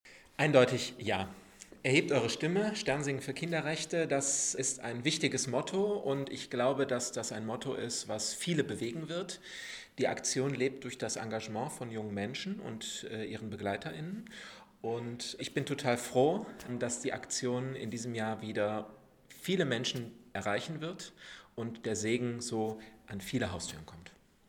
Pressemappe: Bundesweite Eröffnung in Paderborn - Pressekonferenz Audios